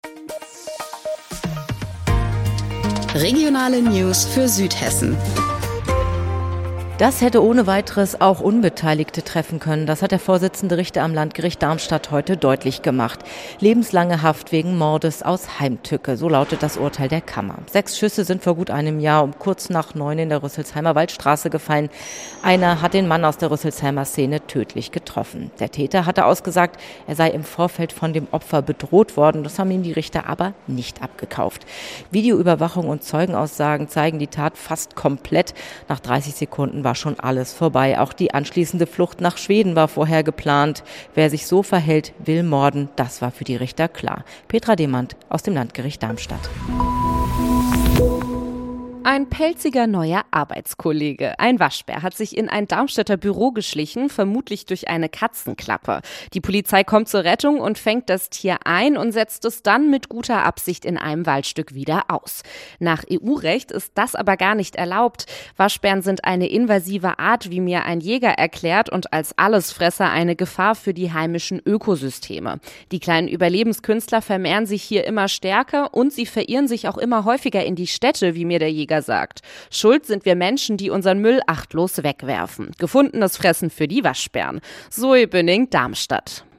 Mittags eine aktuelle Reportage des Studios Darmstadt für die Region